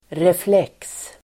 Uttal: [refl'ek:s]